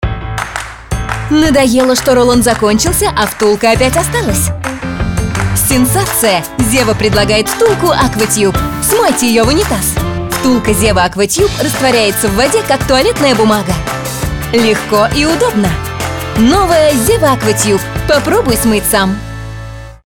Жен, Рекламный ролик/Молодой